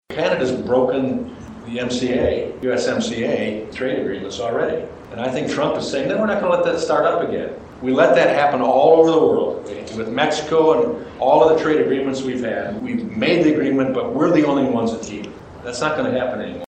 That question was one of many asked during a Legislative Breakfast held last week with U.S. Congressman Tim Walberg at Glen Oaks Community College.